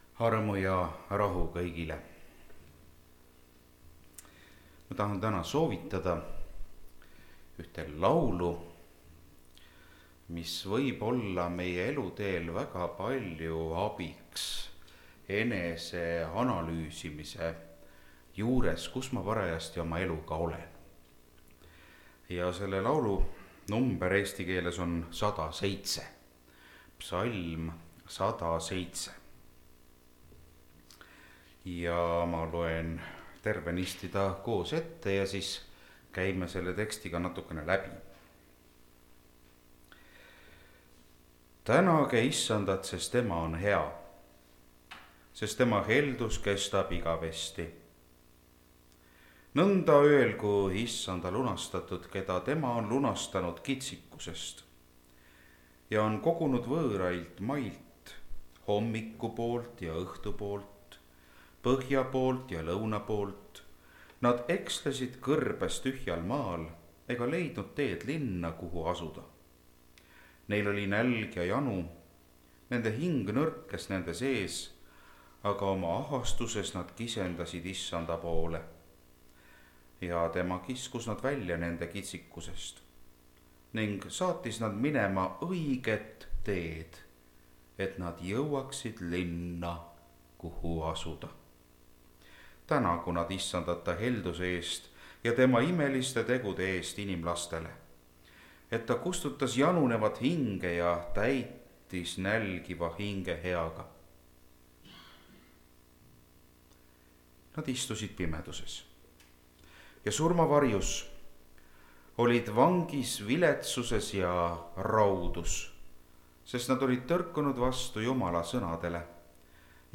Tänamise võlu (Rakveres)
Jutlused